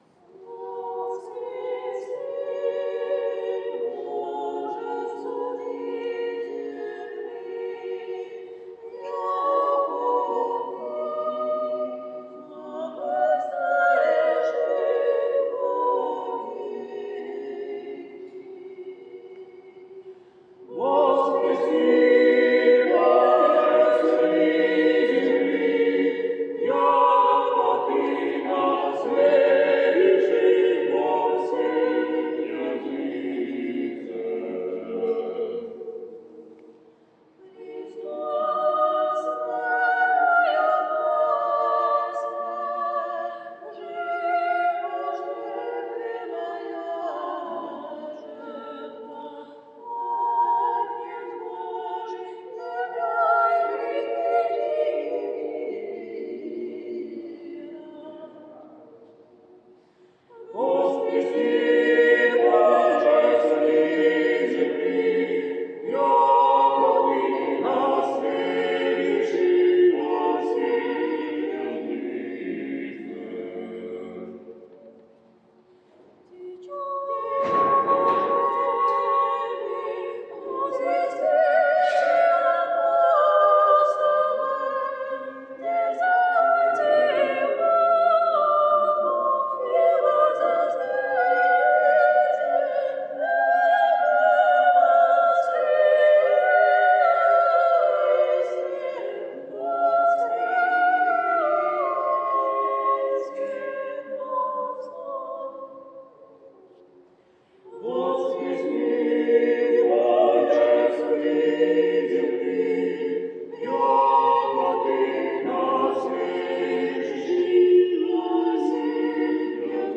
По окончании чтения Паремий хор храма исполнил отрывок из 81 псалма «Воскреси, Боже…» .